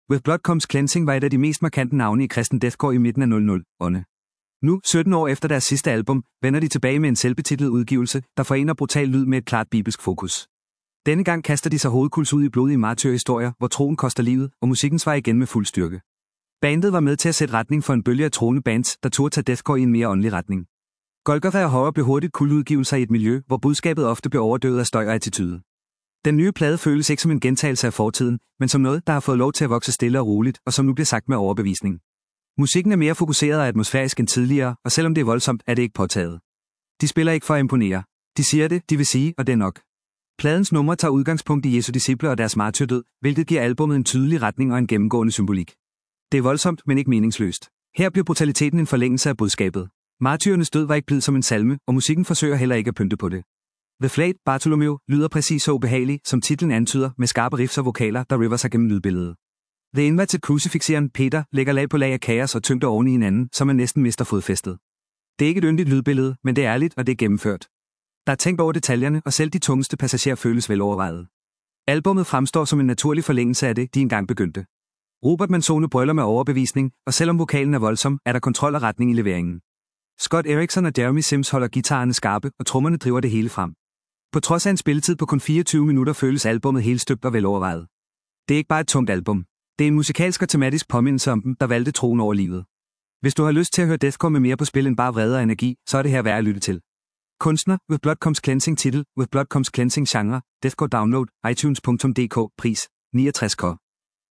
kristen deathcore